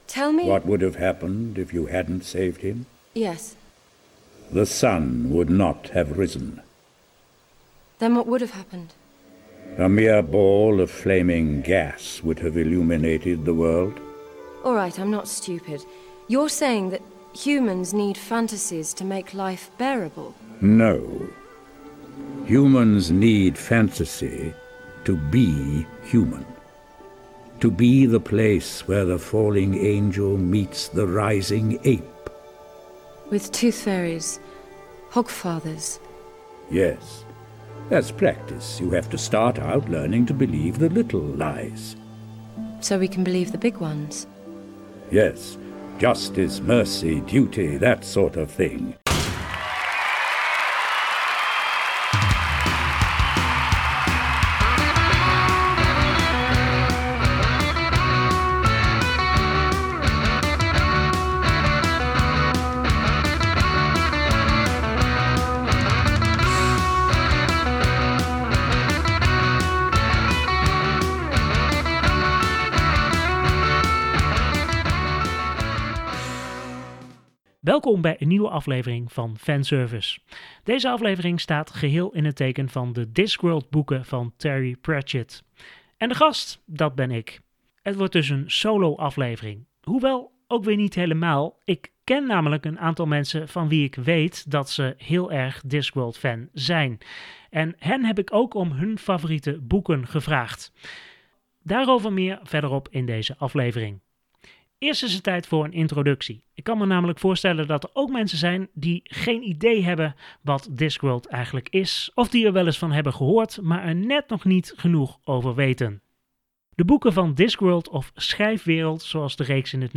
Daarnaast laat ik ook een paar andere Discworld-fans aan het woord.